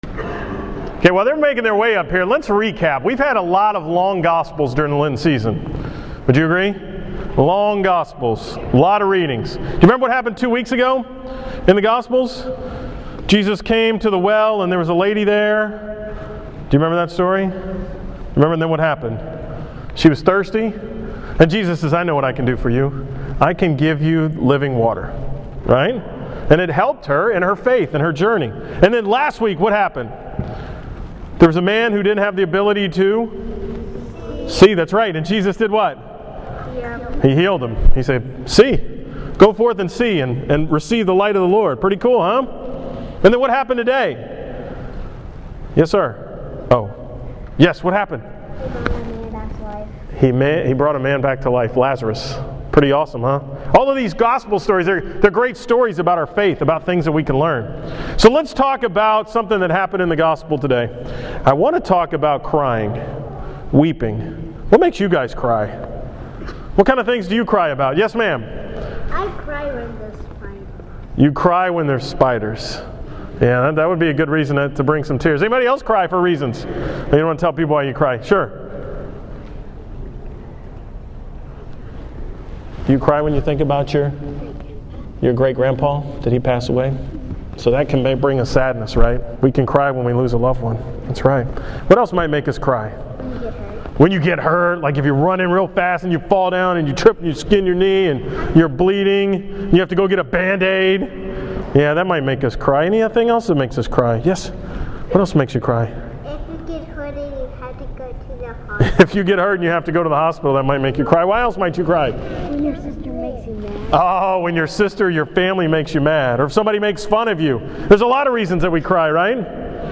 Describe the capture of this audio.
From the 5 pm Mass on April 6